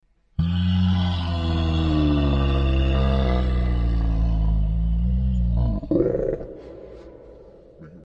描述：无人机的声音是激烈和可怕的。